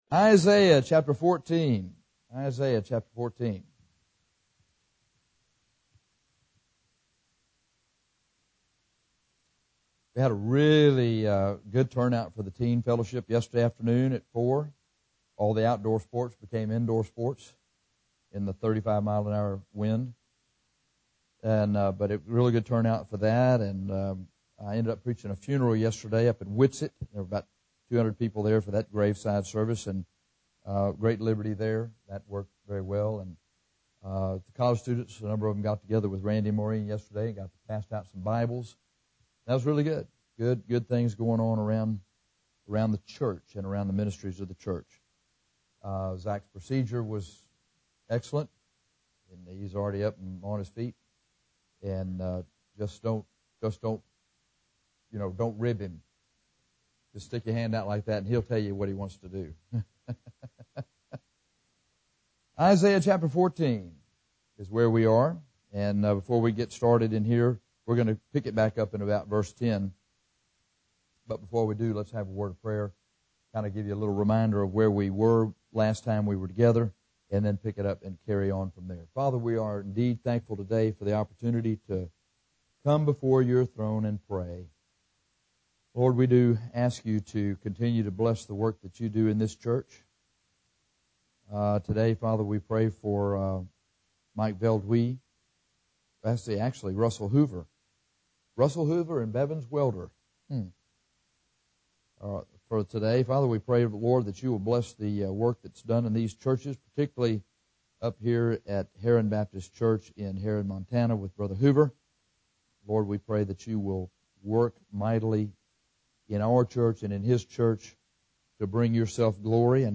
This is a Sunday school lesson about the Devil’s fall. The devil promotes himself to gain worship and his ultimate desire is to replace Jesus Christ as God.